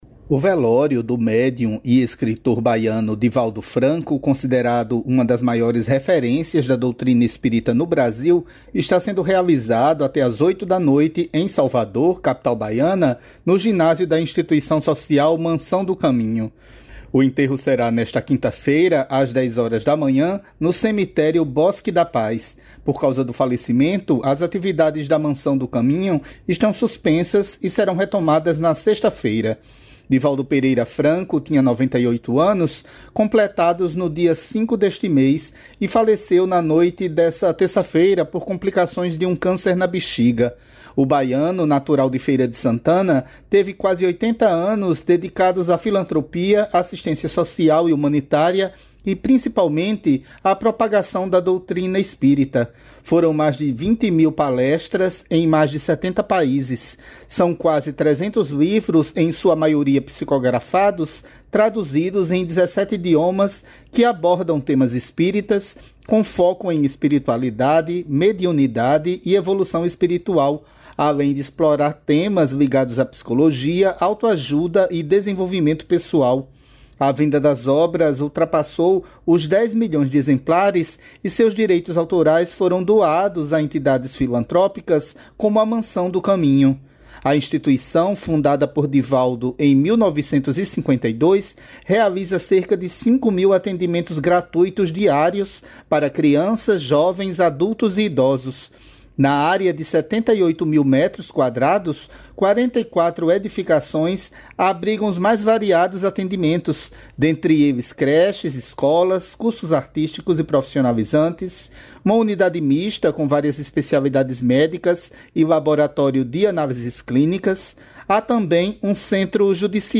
Rádio Agência